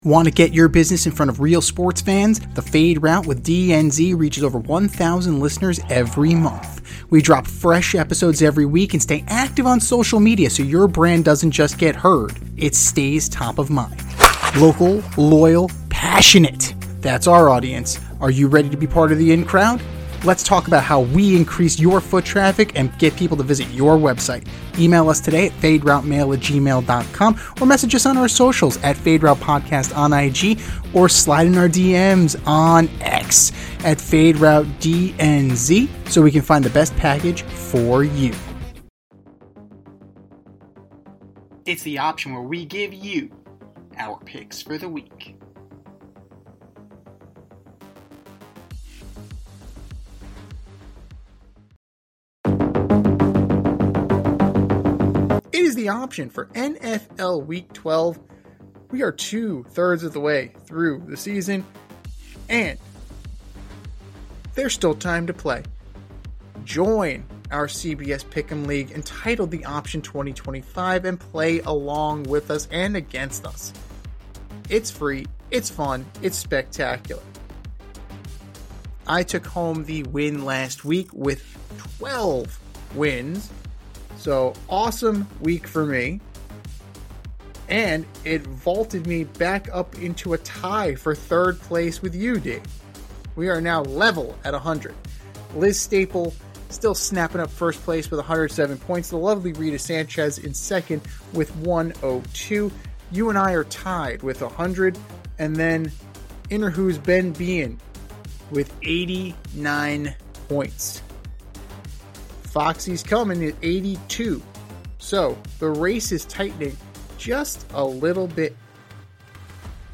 two veteran sports aficionados and lifelong friends